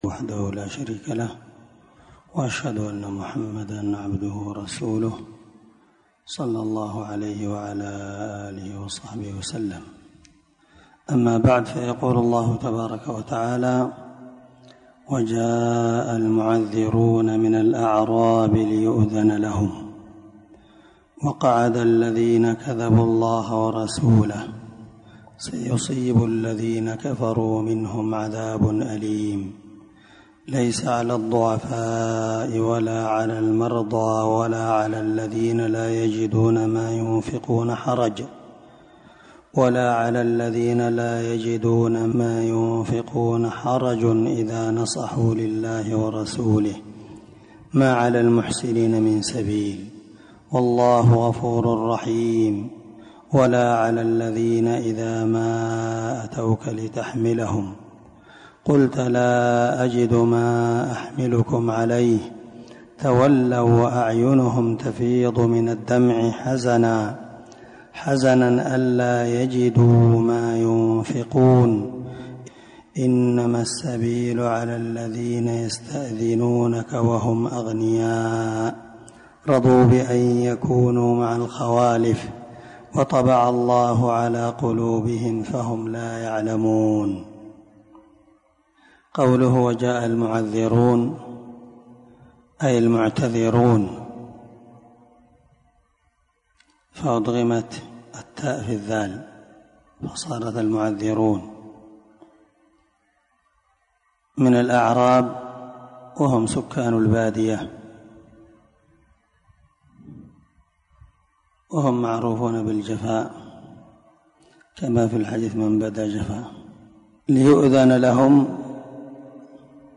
568الدرس39تفسير آية ( 90_93) من سورة التوبة من تفسير القران الكريم مع قراءة لتفسير السعدي